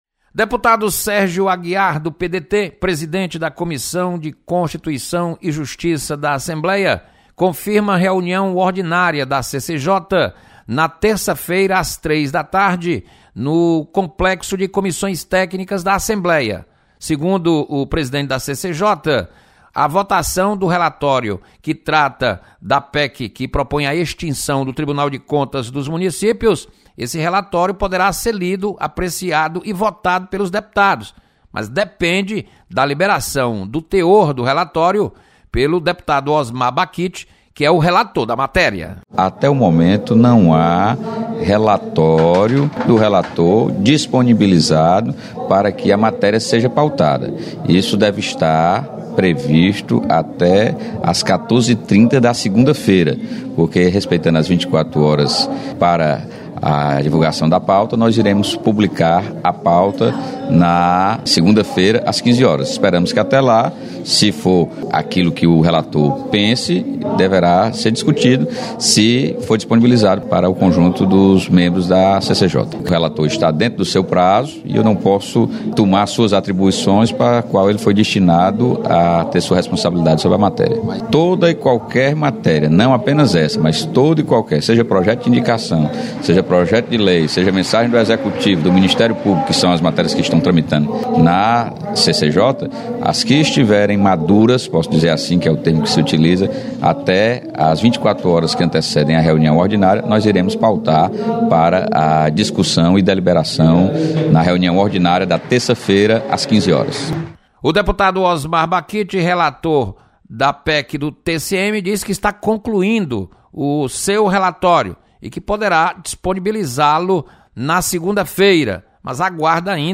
CCJ Vai realizar reunião para debater PEC que visa extinção do TCM. Repórter